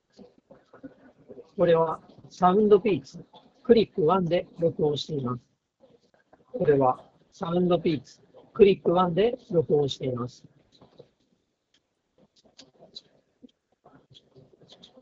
通話時のノイズキャンセリング
スピーカーから雑踏音をそこそこ大きなボリュームで流しながらマイクで収録した音声がこちら。
ノイキャン効果がかなり優秀。これなら通話相手が聞き取りにくいことはなさそうです。
マイクも若干こもり気味なものの悪くはないので、仕事でのちょっとした打ち合わせ程度であれば十分使えると思います。
soundpeats-clip1-voice.m4a